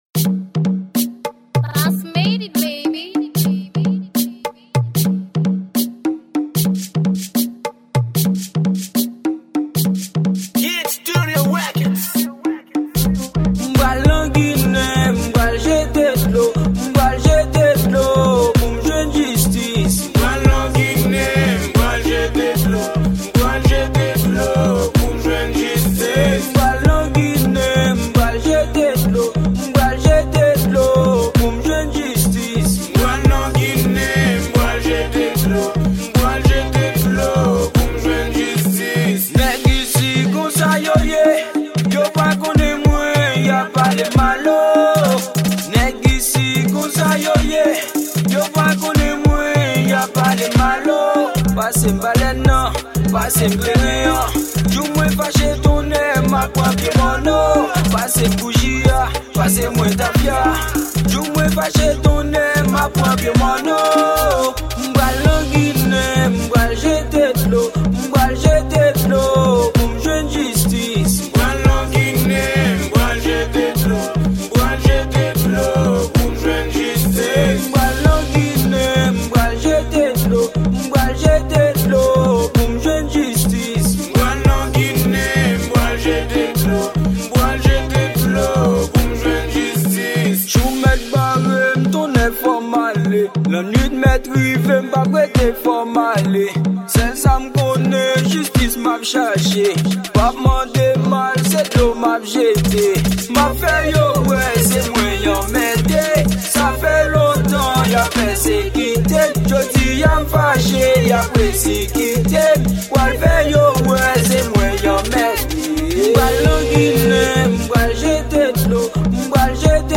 Genre: RASIN.